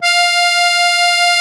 MUSETTE 1.13.wav